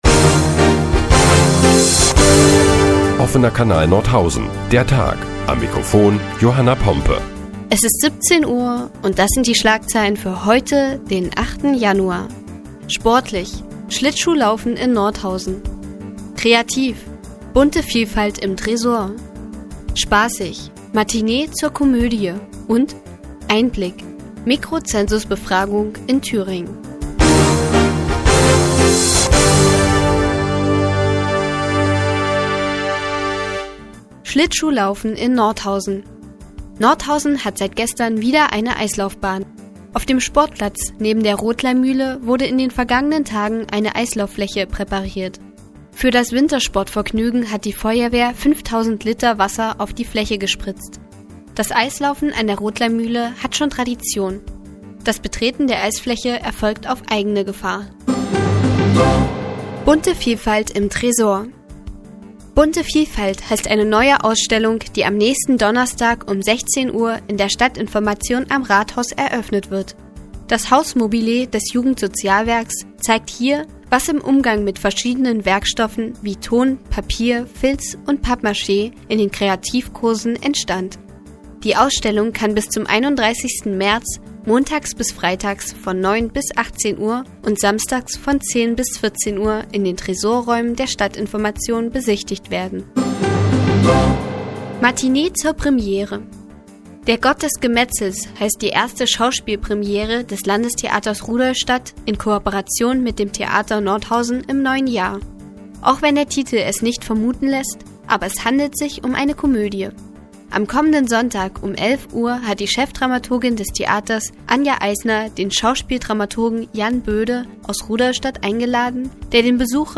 Die tägliche Nachrichtensendung des OKN ist nun auch in der nnz zu hören. Heute geht es unter anderem um eine Eisfläche in Nordhausen und um eine neue Ausstellung in der Stadtinformation.